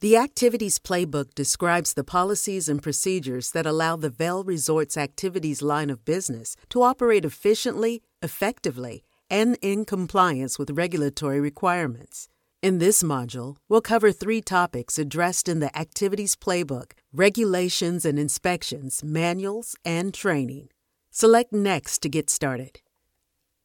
Female Voice Over, Dan Wachs Talent Agency.
Mature, Intelligent, Expressive
eLearning